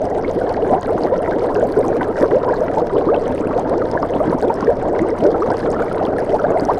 cauldron.wav